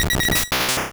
Cri de Doduo dans Pokémon Rouge et Bleu.